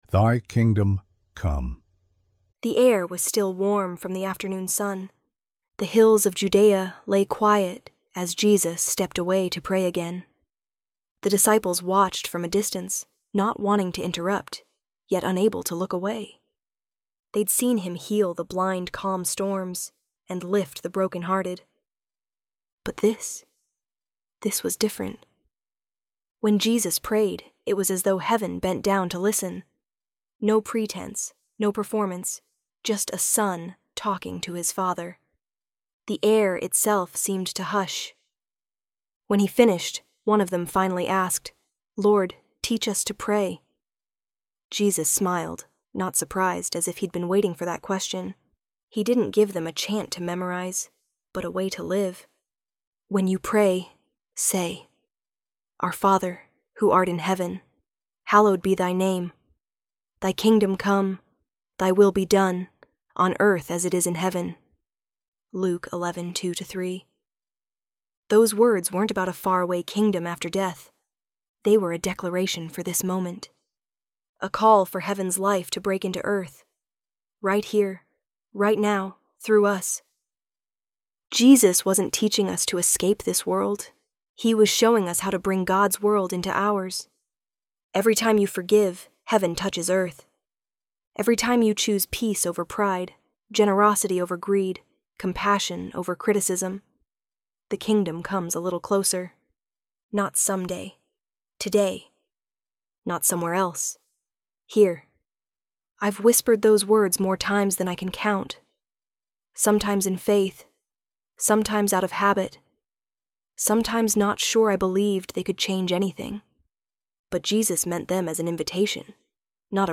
ElevenLabs_Thy_Kingdom_Come.mp3